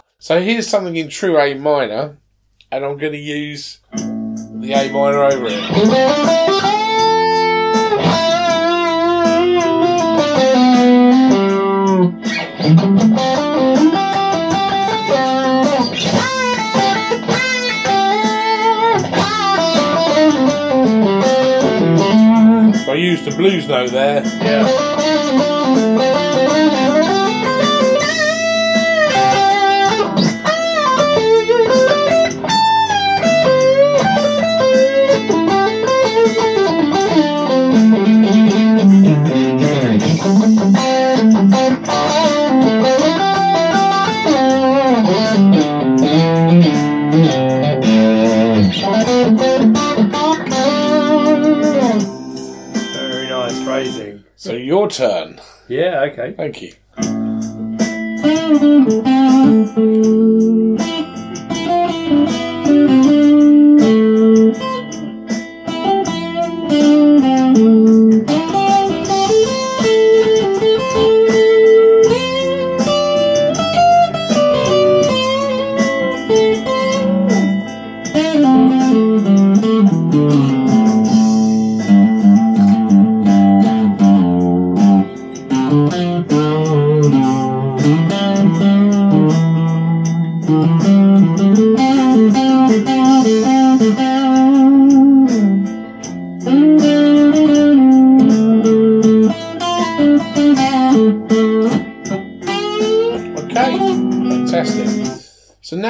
Punctuated by demonstrations and improvisations throughout over backing tracks from YouTube, we hope that you gain some licks, ideas and ways of looking at blues improvisation which are useful and informative.
An example demo:
minor-jam1.mp3